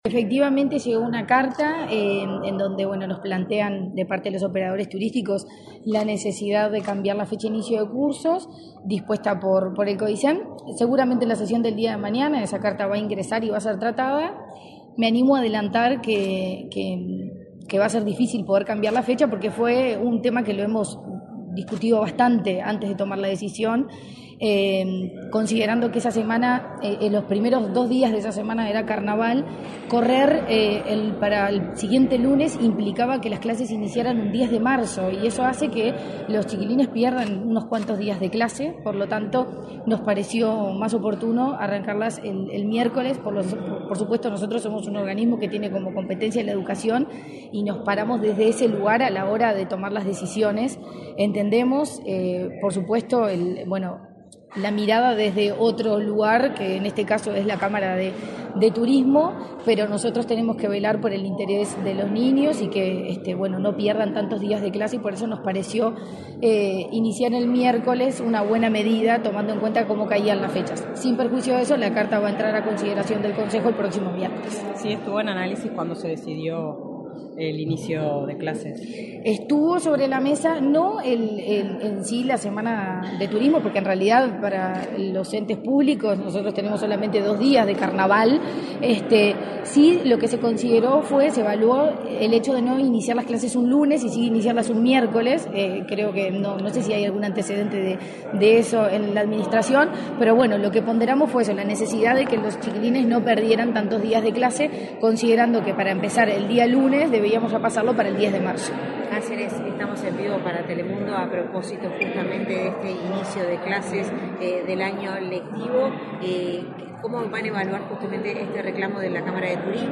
Declaraciones de la presidenta de la ANEP, Virginia Cáceres
La presidenta de la Administración Nacional de Educación Pública (ANEP), Virginia Cáceres, dialogó con la prensa, antes de participar en la firma de